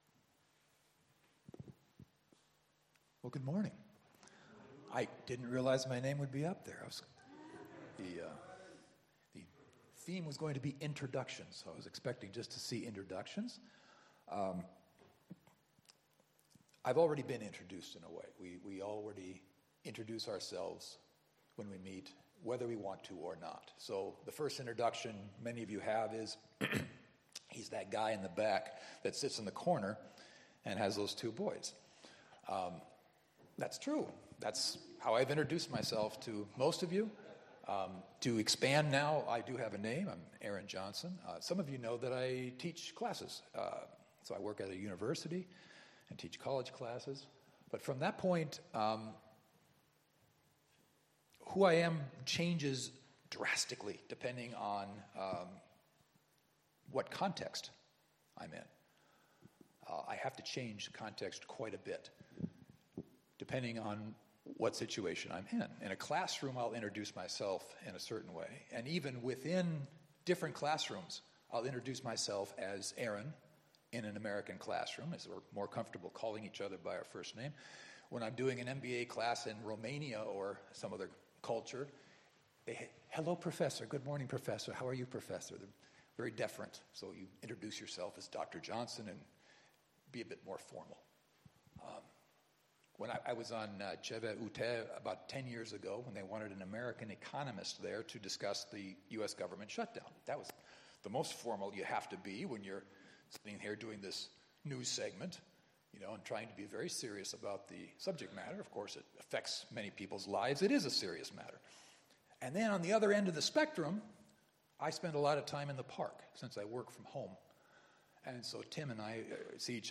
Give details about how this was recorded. Sermons from Sunday worship services at ESUMC Prague, as well as other Christian messages, in audio or video format and with Bible passages included.